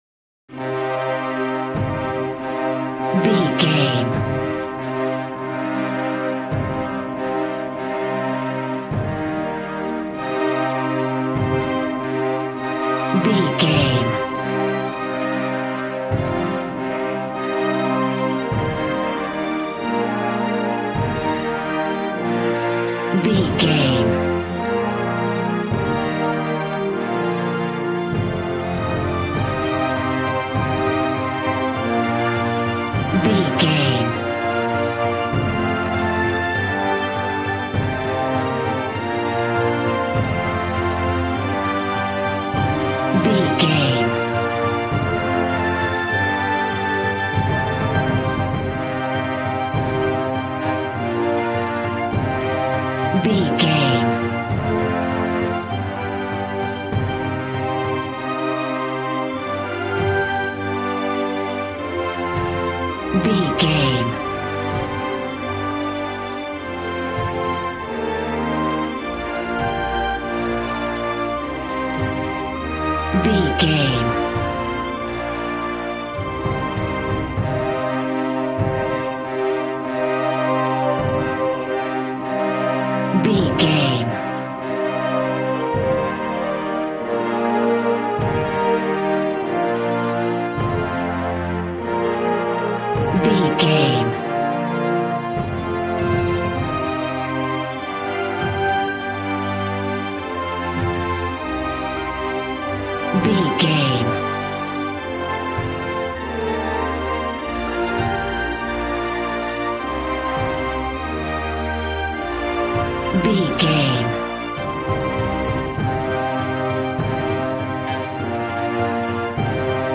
Aeolian/Minor
brass
strings
violin
regal